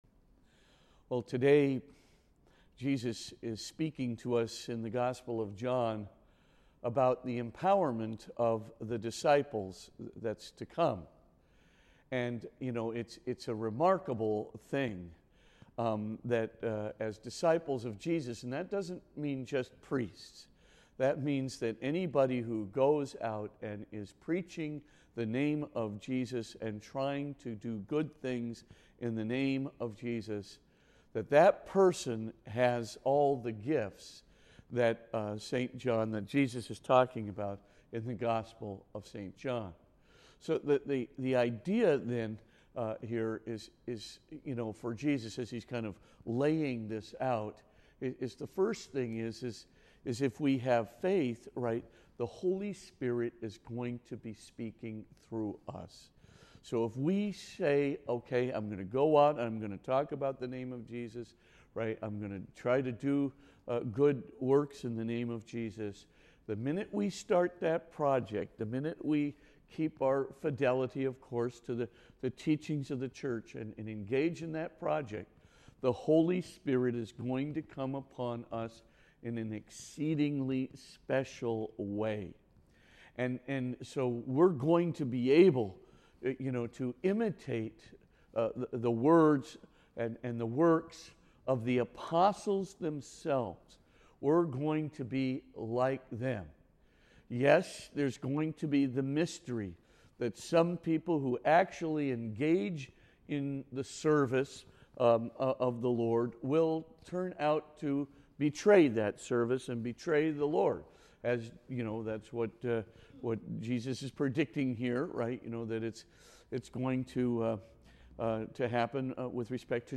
Podcast (fr_spitzer_homilies): Play in new window | Download